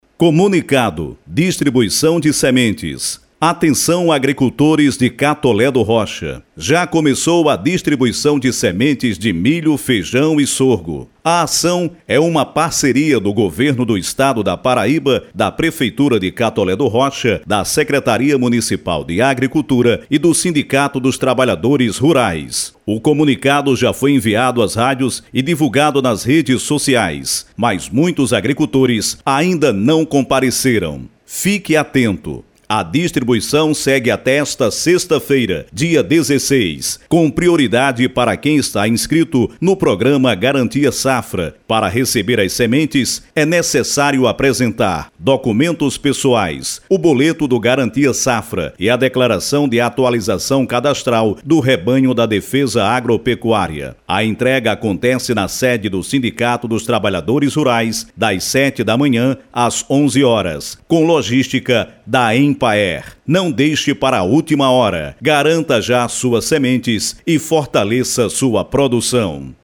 COMUNICADO-–-DISTRIBUICAO-DE-SEMENTES.mp3